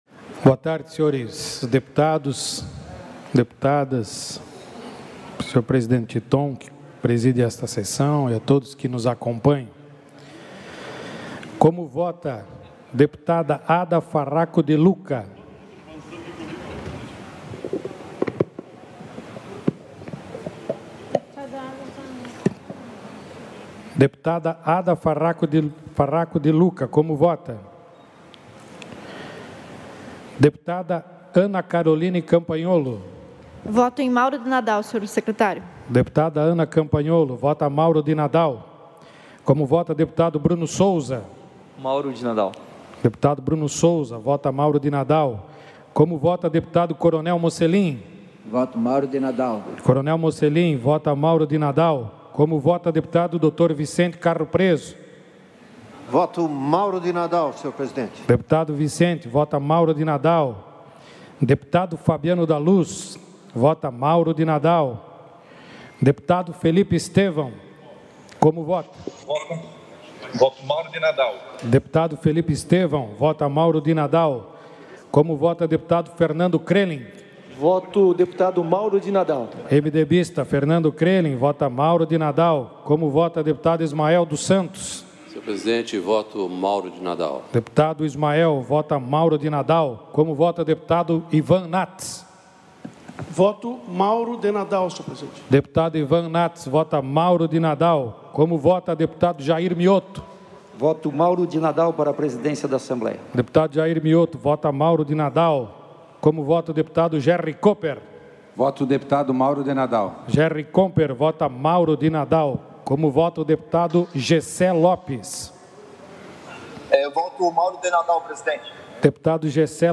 Confira os pronunciamentos dos deputados durante a sessão preparatória desta segunda-feira (1º) para a eleição do presidente e da Mesa Diretora da Assembleia Legislativa para o biênio 2021-2023.